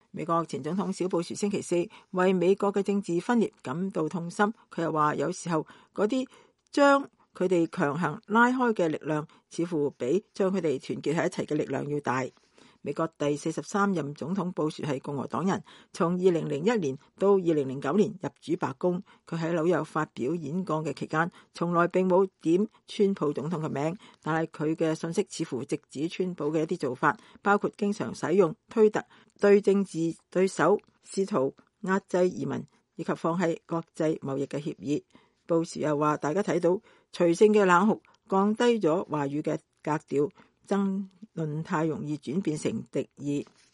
前總統小布殊10月19日在紐約發表演說。